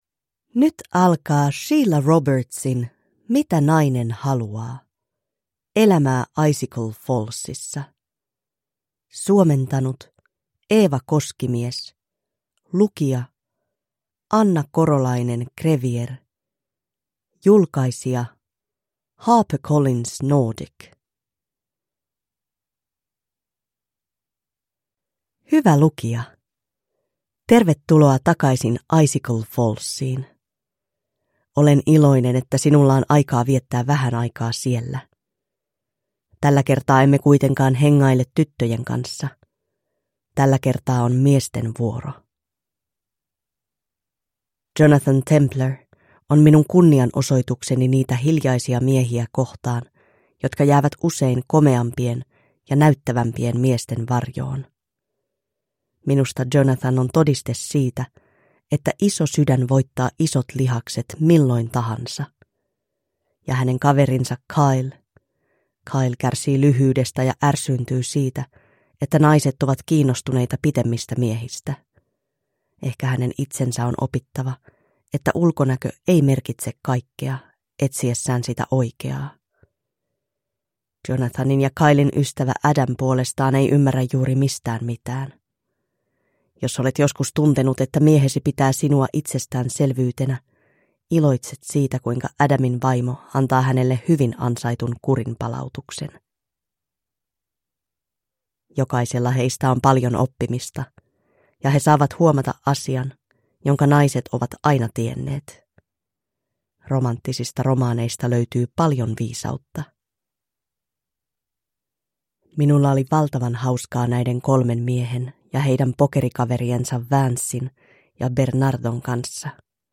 Mitä nainen haluaa – Ljudbok